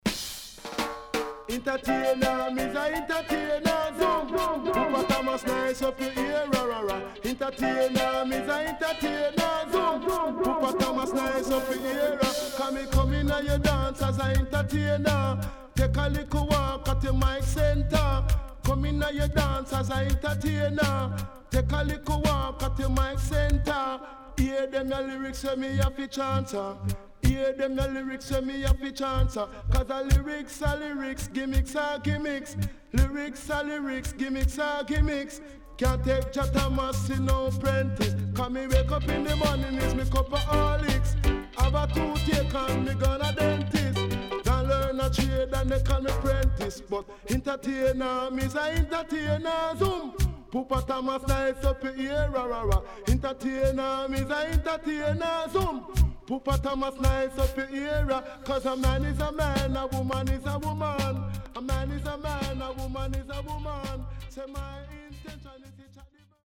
Nice Deejay